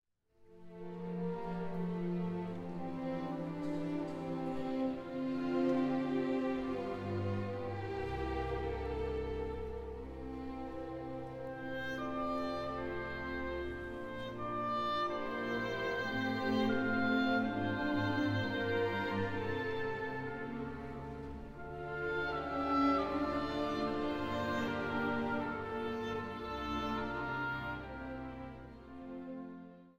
orgel
piano
sopraan
tenor
bariton